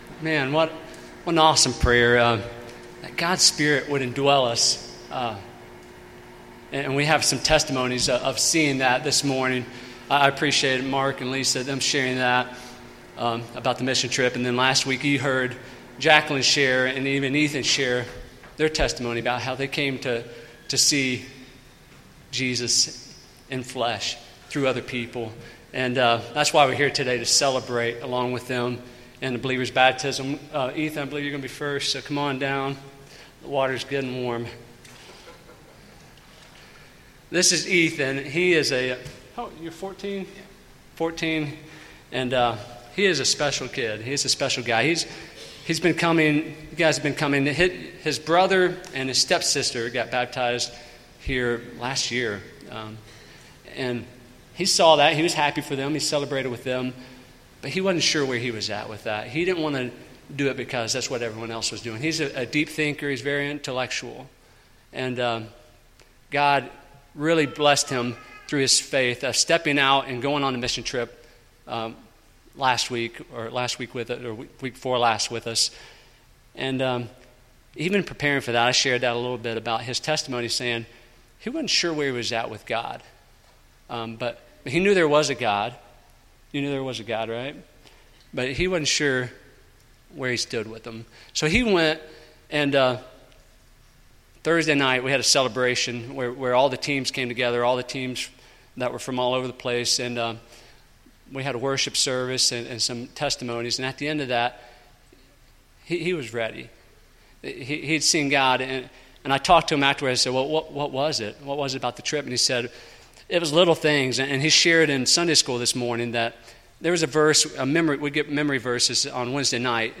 Personal Testimonies